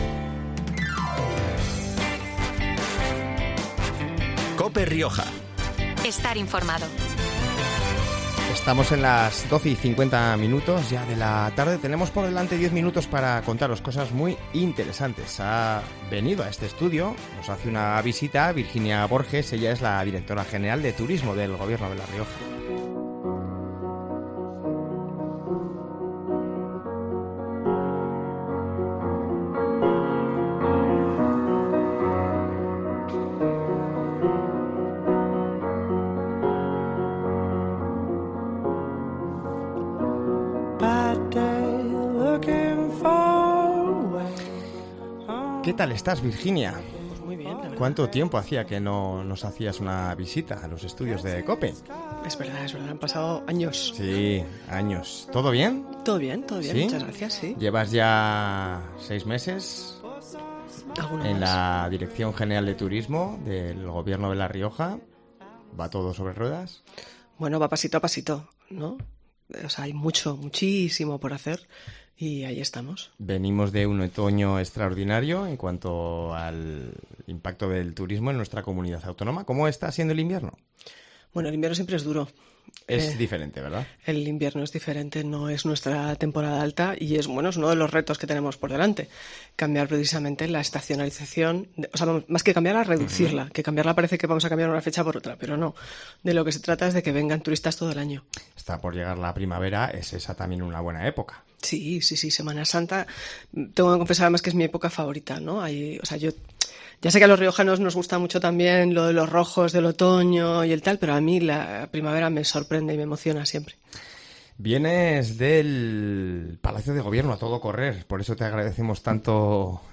Un encuentro en el que, como ha subrayado luego Virginia Borges en una entrevista en COPE Rioja, “más de 100 empresas de La Rioja podrán participar en un programa formativo y de promoción con el que impulsar y acompañar a todos en la necesaria profesionalización del sector, además de generar en el estand diferentes espacios de trabajo y sinergias con las que avanzar de forma coral en la promoción de La Rioja”.